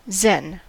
Ääntäminen
Vaihtoehtoiset kirjoitusmuodot Zen Ääntäminen US Tuntematon aksentti: IPA : /ˈzɛn/ Haettu sana löytyi näillä lähdekielillä: englanti Käännös Konteksti Adjektiivit 1.